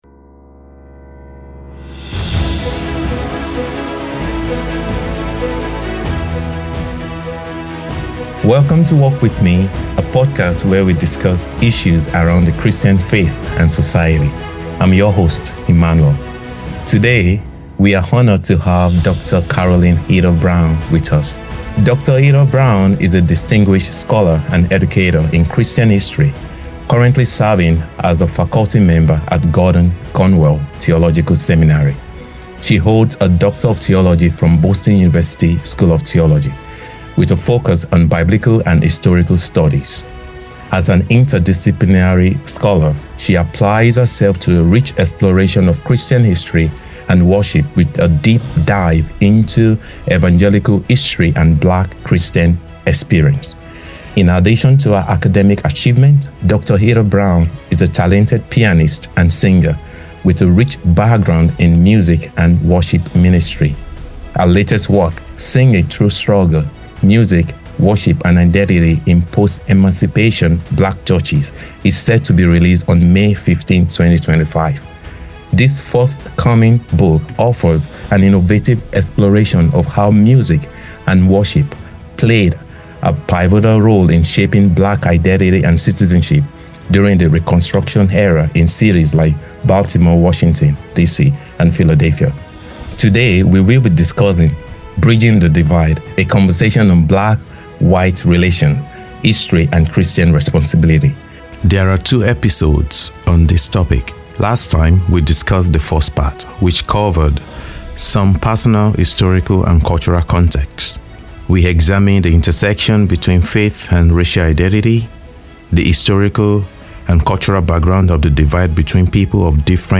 A Conversation on Black-White Relations, History, and Christian Responsibility